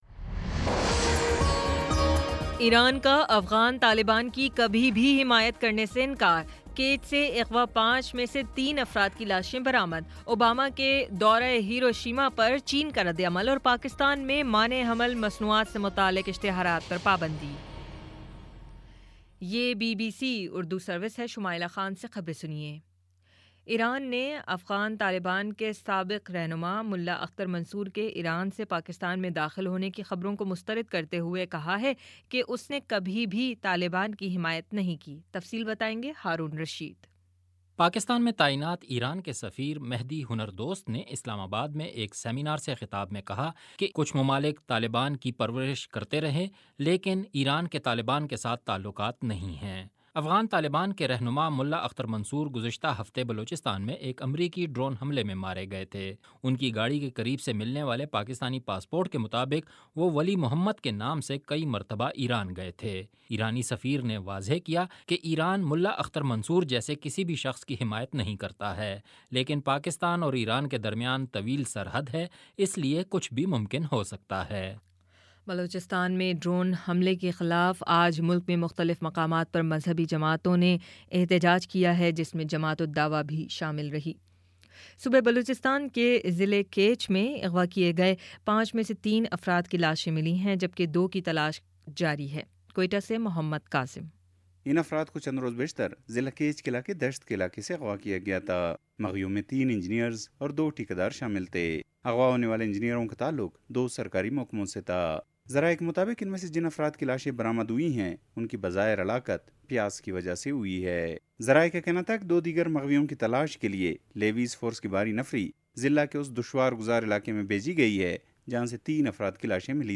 مئی 27 : شام چھ بجے کا نیوز بُلیٹن